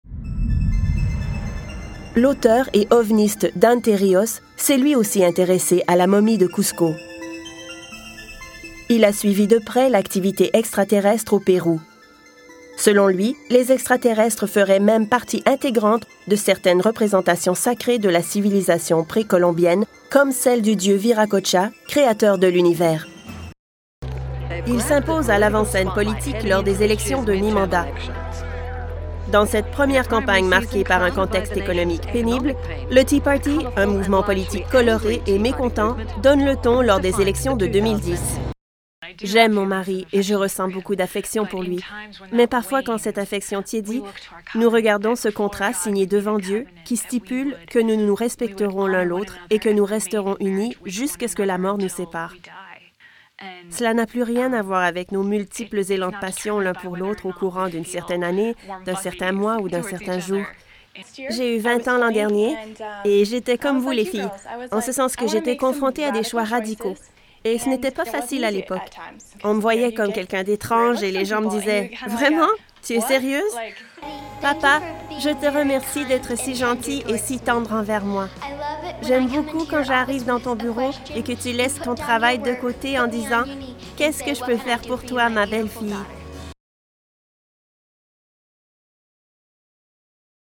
Narration - FR